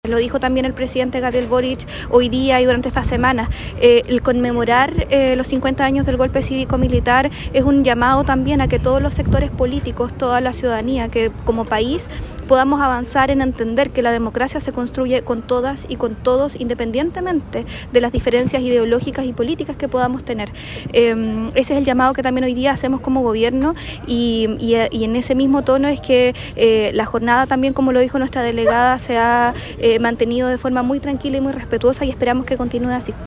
La Seremi de Gobierno enfatizó que la conmemoración de los 50 años del Golpe de Estado, debe responder al llamado a la ciudadanía para comprender que la democracia se construye entre todos y todas.